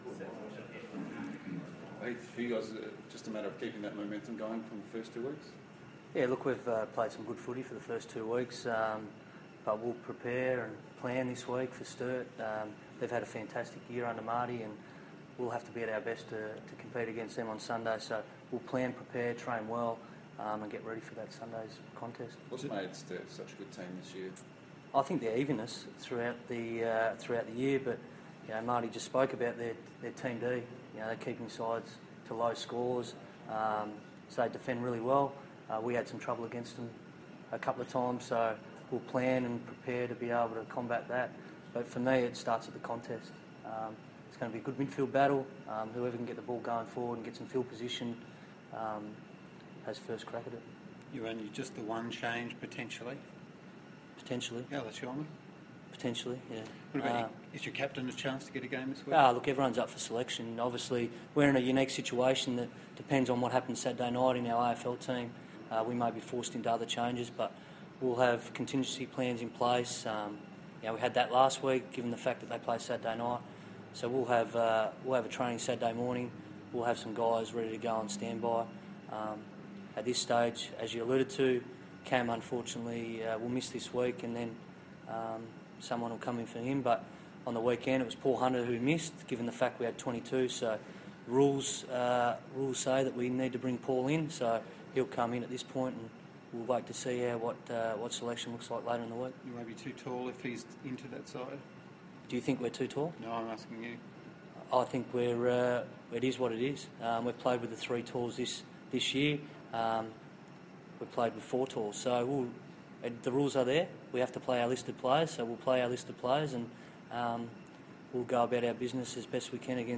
SANFL press conference